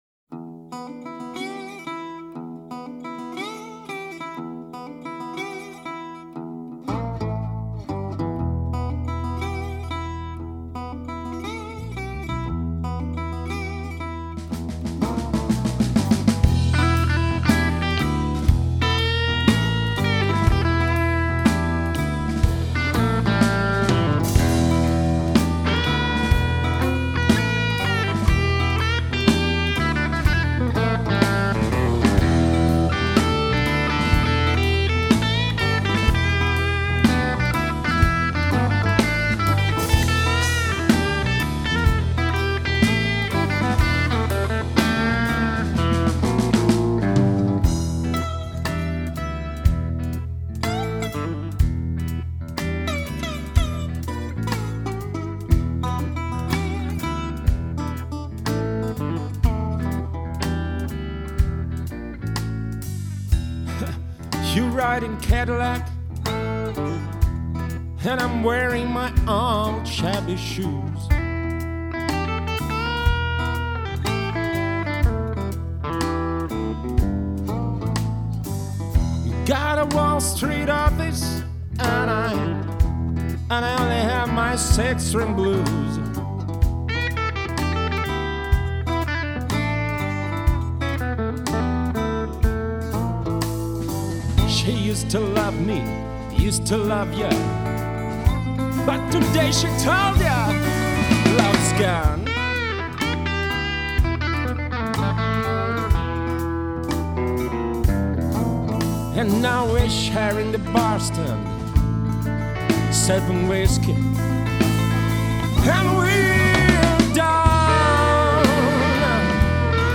Тоже присоединился бы Откопалось несколько работ с практически нетронутой динамикой, такого плана : PS.